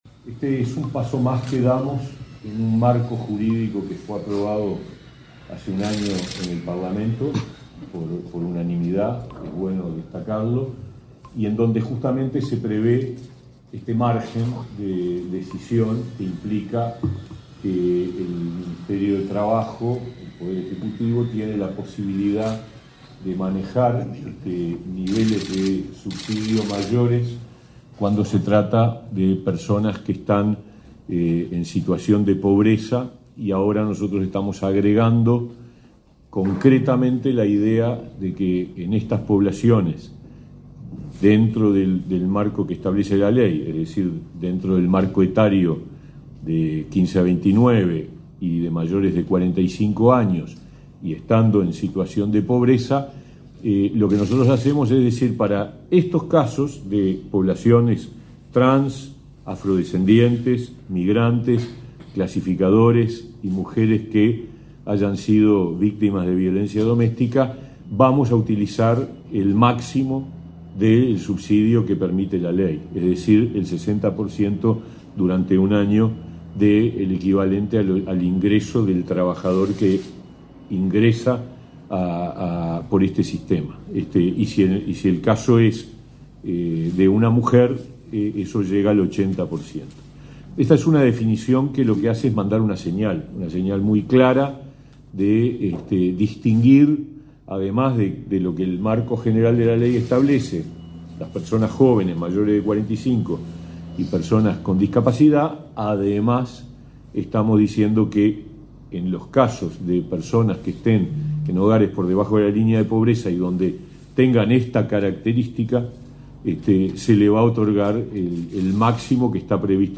Palabras del ministro de Trabajo, Pablo Mieres
El ministro de Trabajo y Seguridad Social, Pablo Mieres, participó de la presentación del programa Colectivos Vulnerables, enmarcado en la Ley de